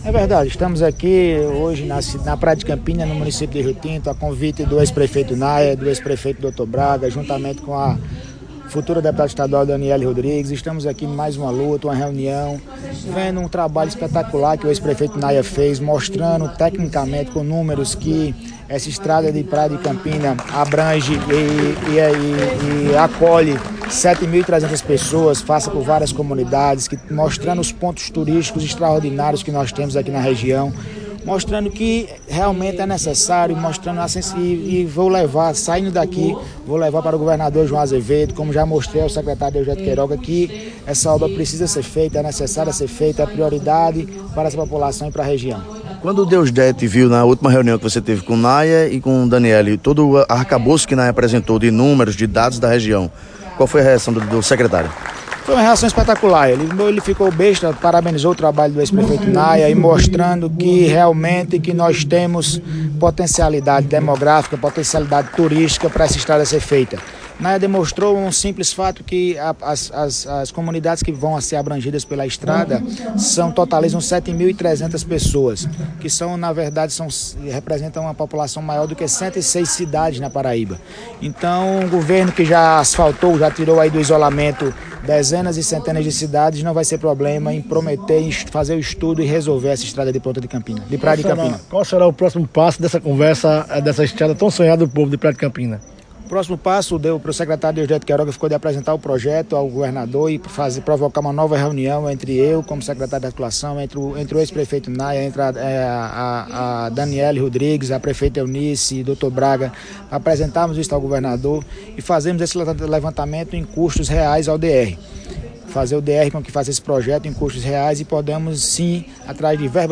Reunião que debateu construção de estrada, aconteceu em Praia de Campina.
Murilo-Galdino.wav